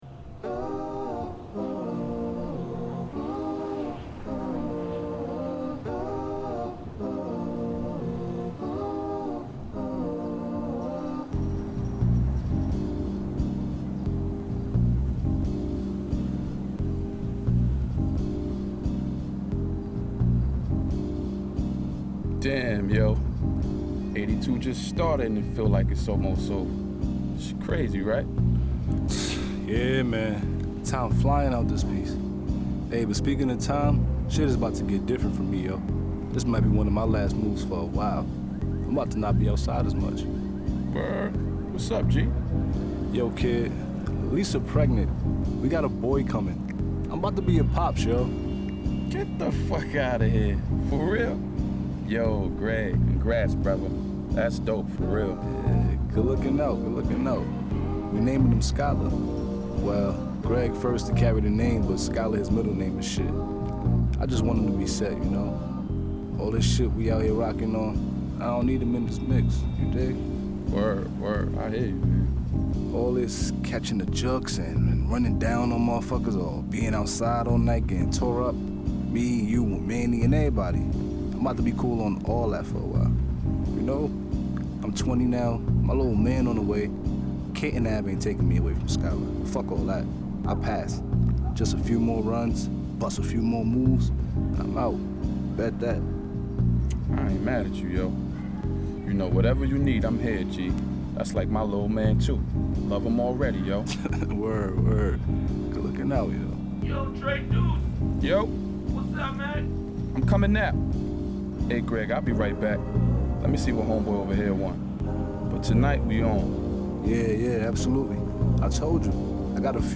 男性シンガー
骨太さと洗練を併せ持ったコンシャスな仕上がり！！
Boom Bap